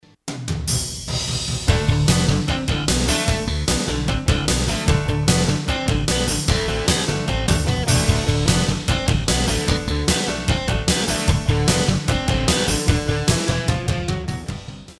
Country Music Samples
Country 91c